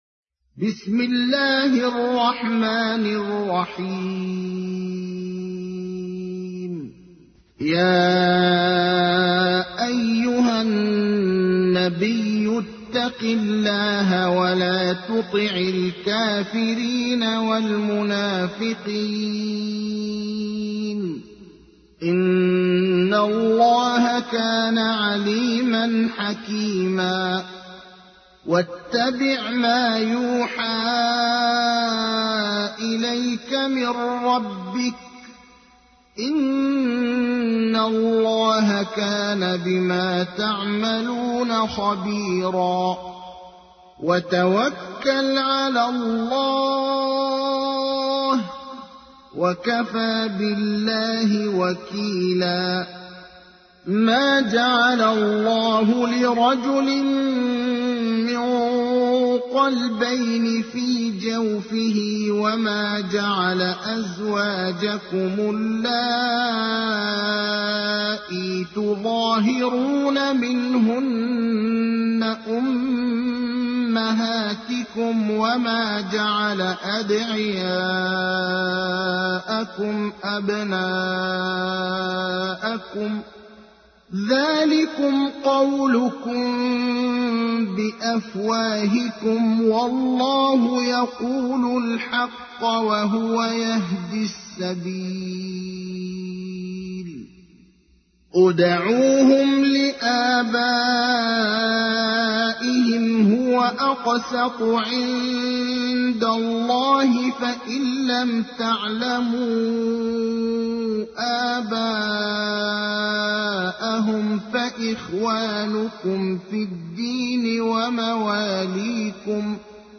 تحميل : 33. سورة الأحزاب / القارئ ابراهيم الأخضر / القرآن الكريم / موقع يا حسين